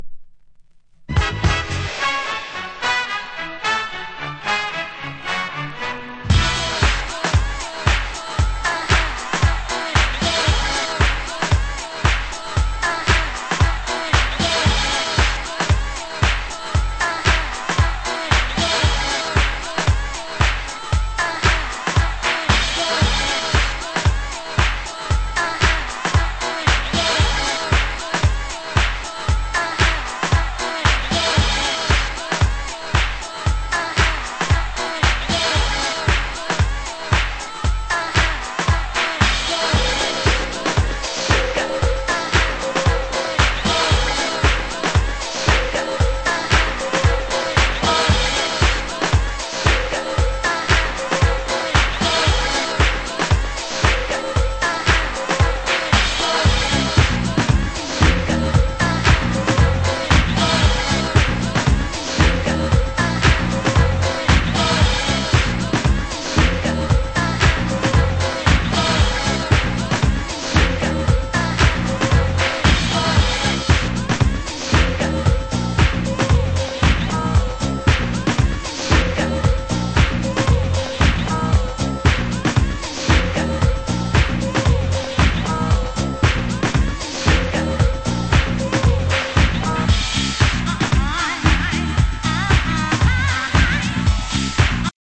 盤質：軽度のスレ・小傷によるチリパチノイズ有